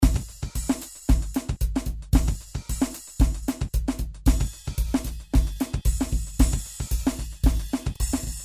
描述：4条鼓的循环。拉丁，不是特别的桑巴，但拉丁似乎不是一个流派的选择。
Tag: 113 bpm Samba Loops Drum Loops 1.43 MB wav Key : Unknown